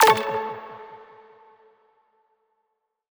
menuBack.wav